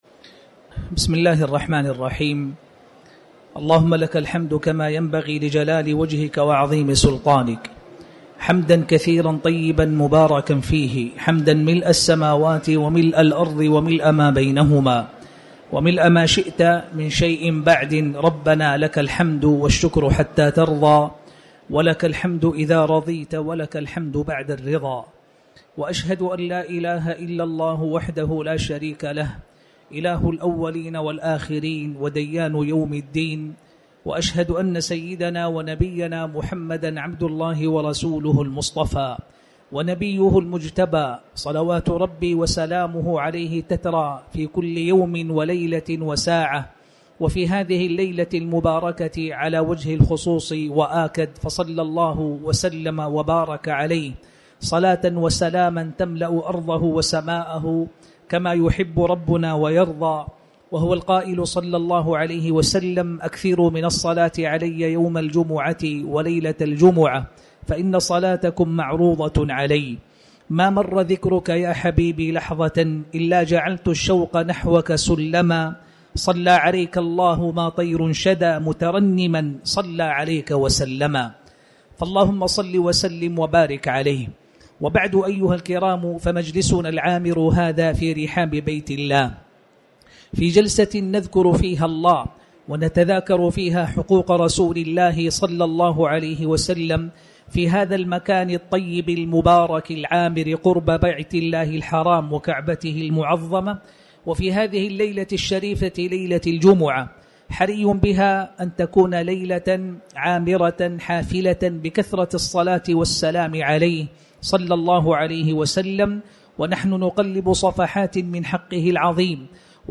تاريخ النشر ١٣ شعبان ١٤٤٠ هـ المكان: المسجد الحرام الشيخ